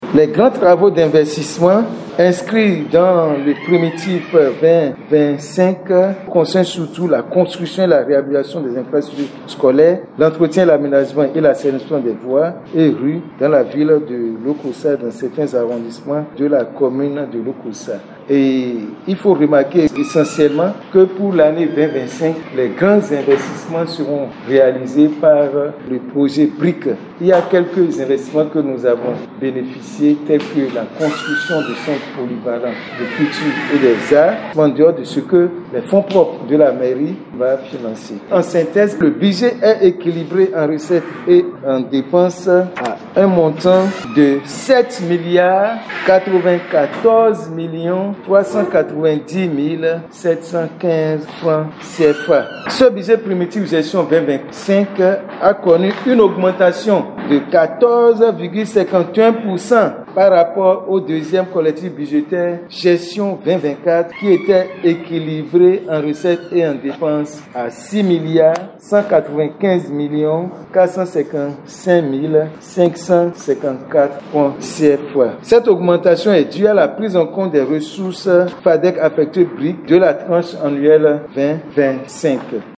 SESSION-BUDGETAIRE-LOKOSSA-2.mp3